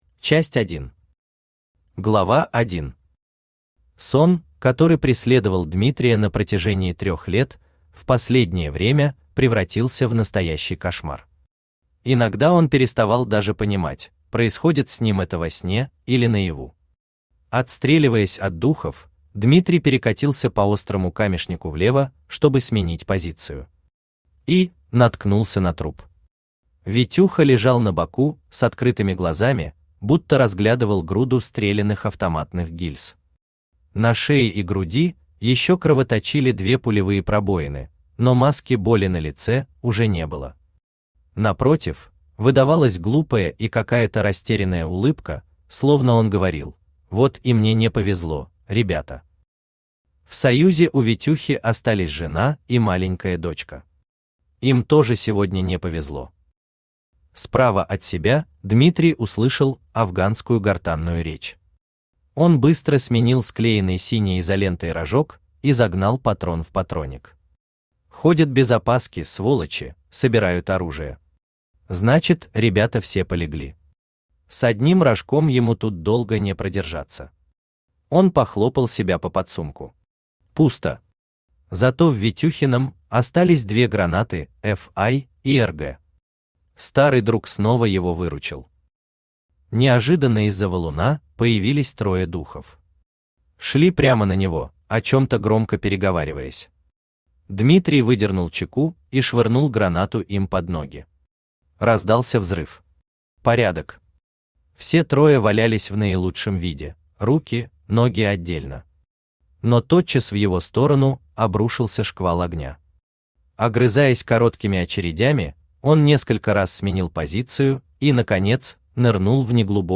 Аудиокнига Русский алмаз | Библиотека аудиокниг
Aудиокнига Русский алмаз Автор А. В. Шульга Читает аудиокнигу Авточтец ЛитРес.